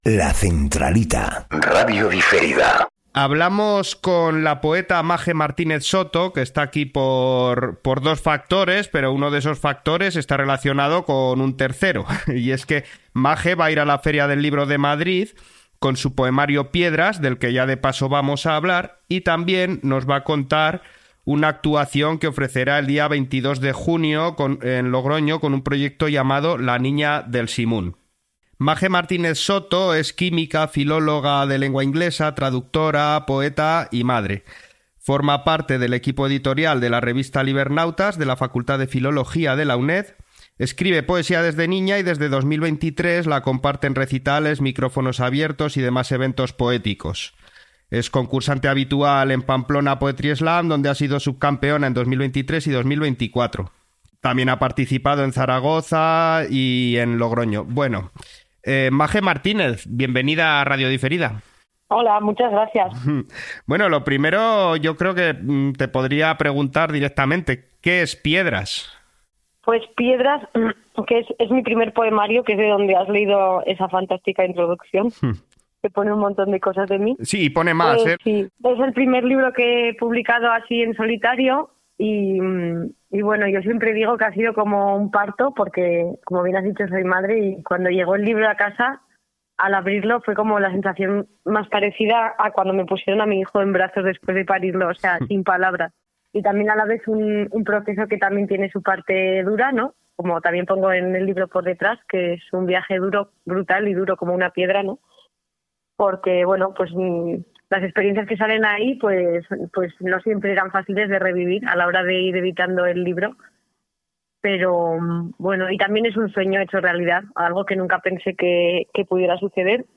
coge la llamada de La Centralita